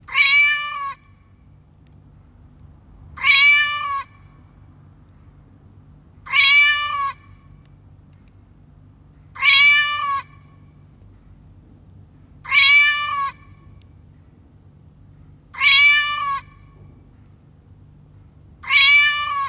From barking dogs to meowing cats and chirping birds, each clock has its own unique voice.